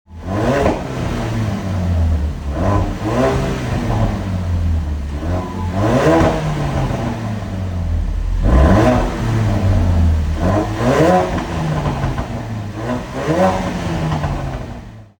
Listen to turbocharged 4-cylinder symphony!
• 2.3L 4-Cylinder EcoBoost Engine
ford-focus-rs-mk3-heritage-edition-375ps-exhaust-revs-NGE.mp3